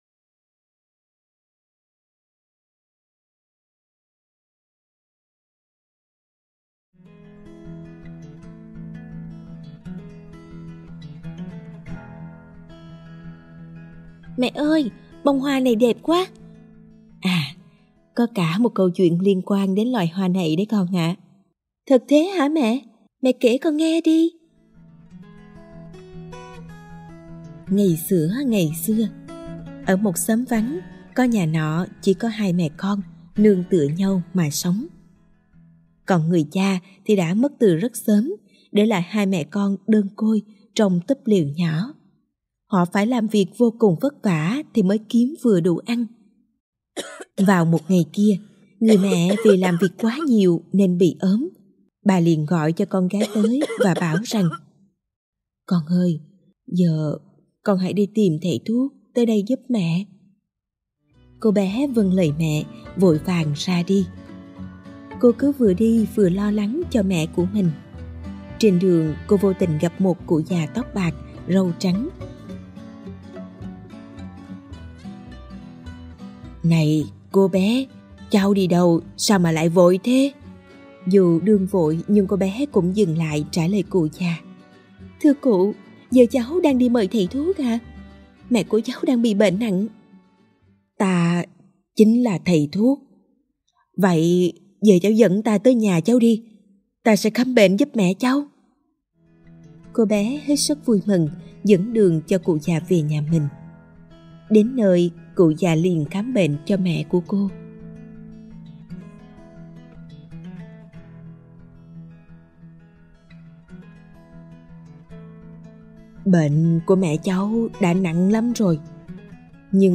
Sách nói | Miền Cổ Tích - Sự Tích Hoa Cúc Trắng